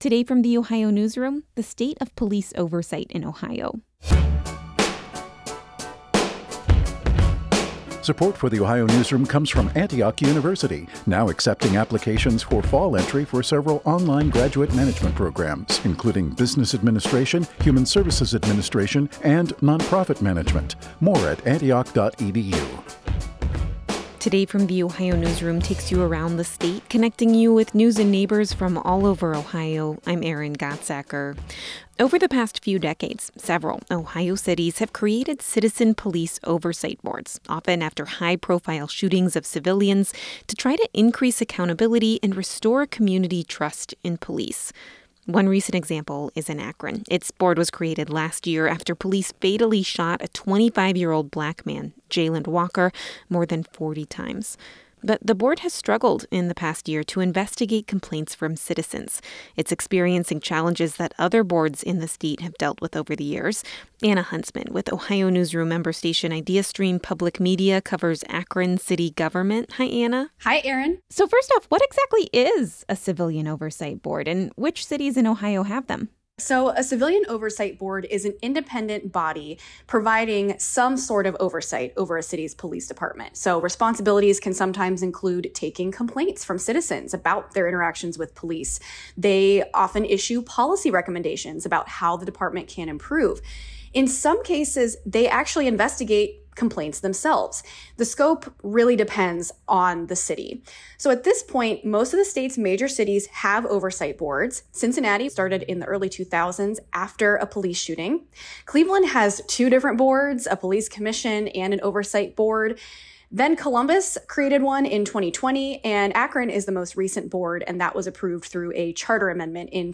This conversation has been lightly edited for clarity and brevity.
civilian-boards-two-way-web-final.mp3